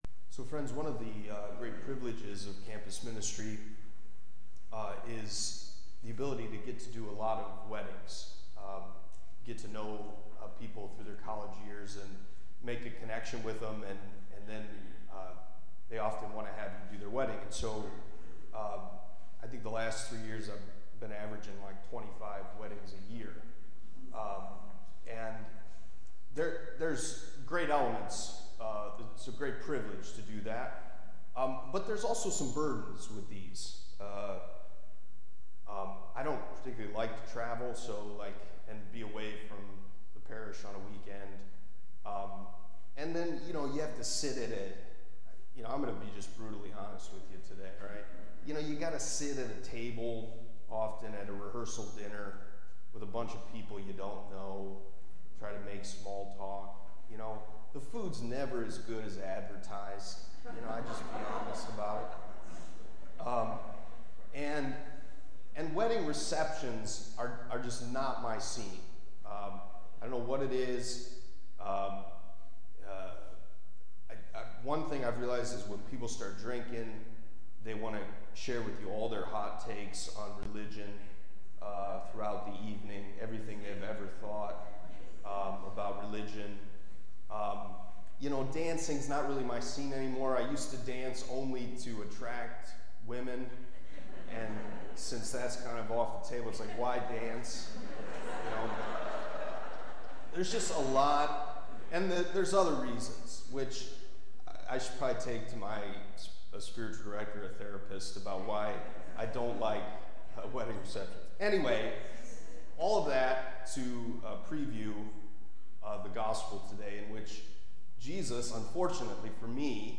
Homily from the Twenty-Eighth Sunday in Ordinary Time, Sunday, October 15